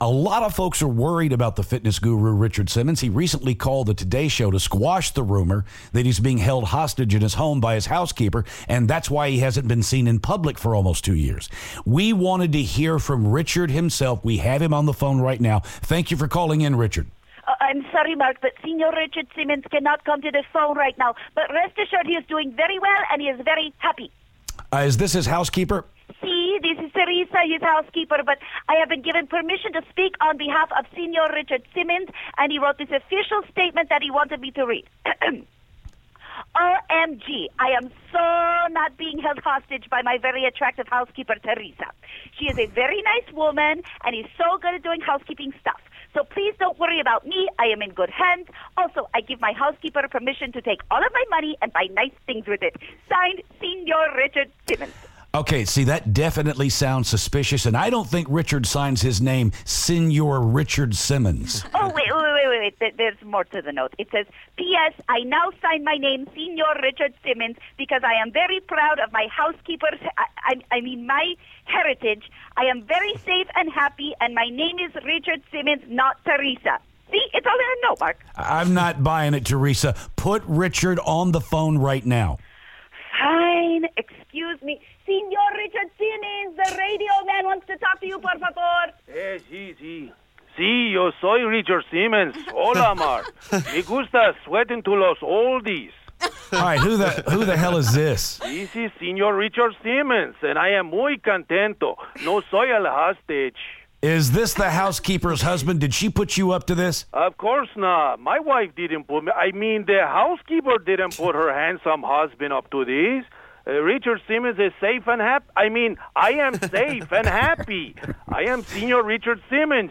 Richard Simmons calls the show?